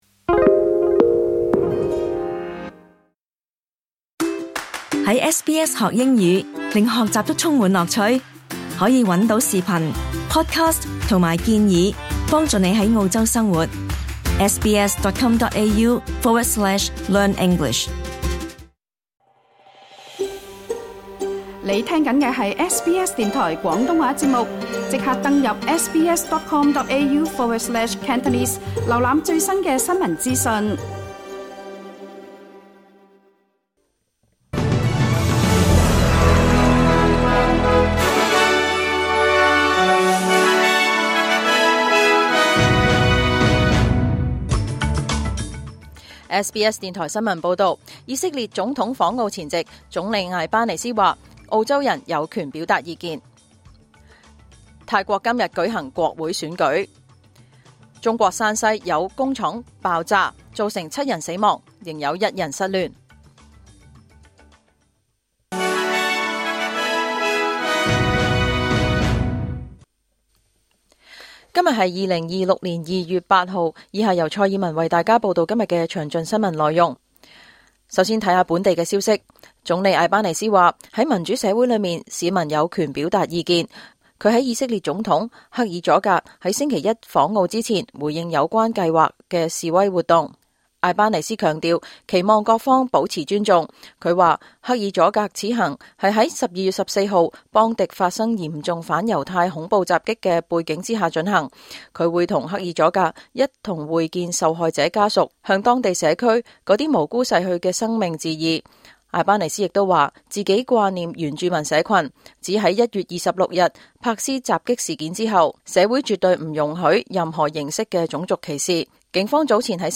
2026 年 2 月 8 日 SBS 廣東話節目詳盡早晨新聞報道。